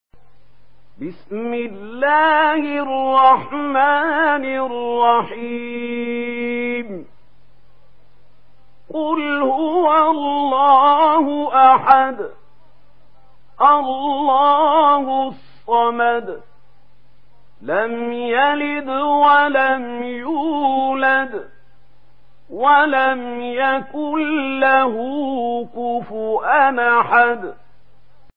Surah আল-ইখলাস MP3 in the Voice of Mahmoud Khalil Al-Hussary in Warsh Narration
Murattal Warsh An Nafi